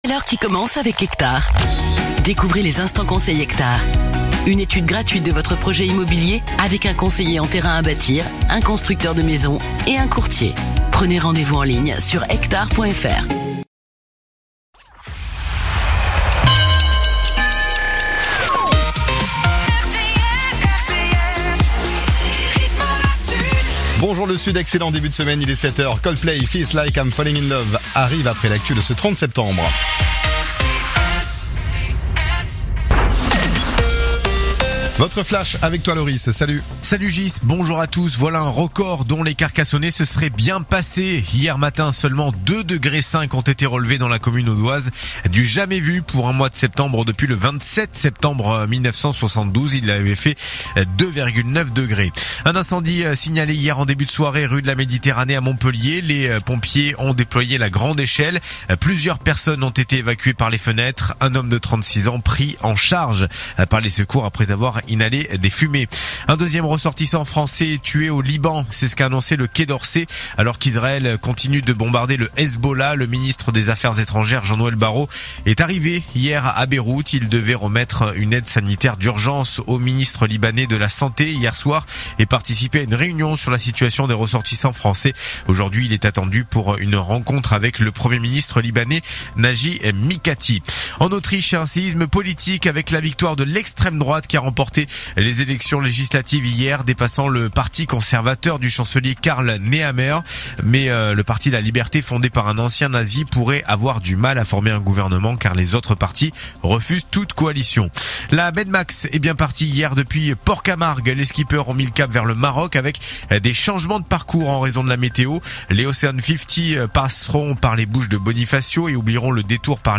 info_mtp_sete_beziers_157.mp3